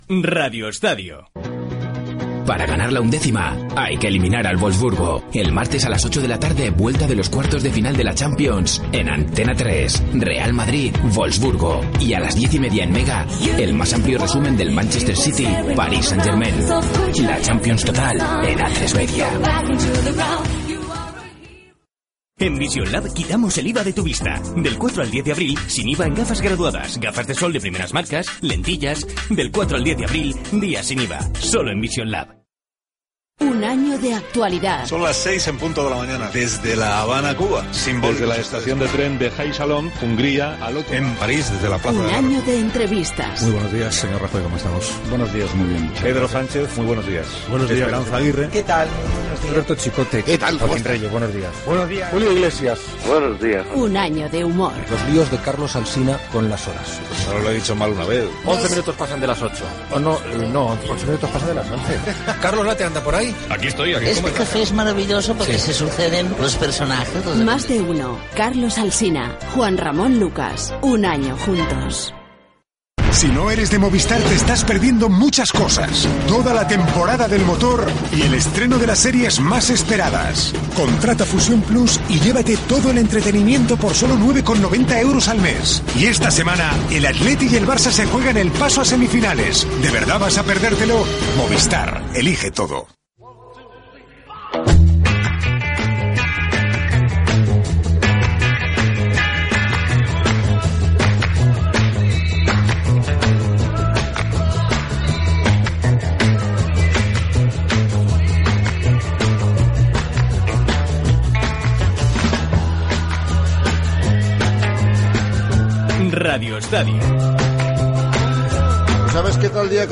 Entrevista a Eusebio Sacristán, entrenador de la Real Sociedad.
Gènere radiofònic Esportiu